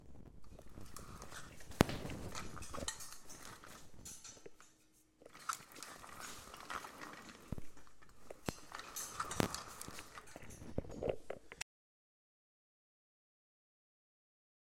描述：A large marble ball rolling in a stainless steel wok lid
标签： Woklid Rolling Marbleball
声道立体声